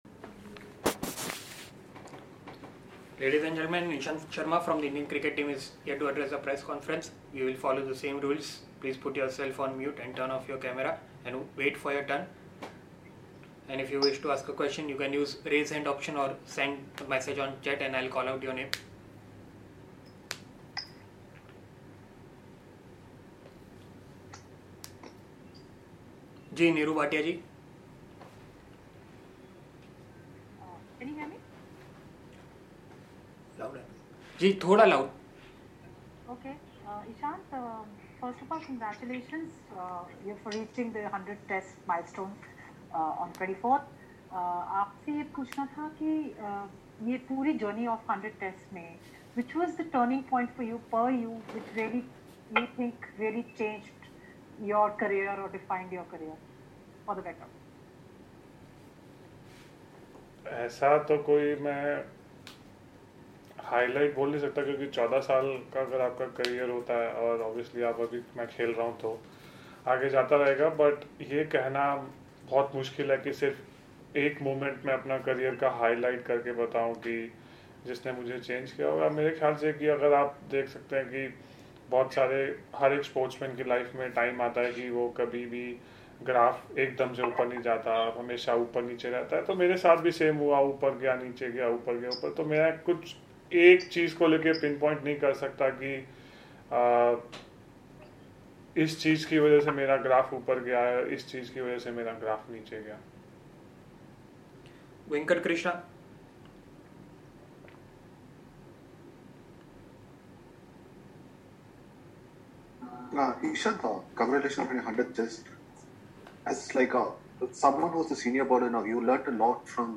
Mr Ishant Sharma, Member, Indian Cricket Team, addressed a virtual press conference ahead of the third day-night Paytm pink-ball Test in Ahmedabad.